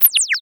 kenney_interfacesounds
minimize_004.ogg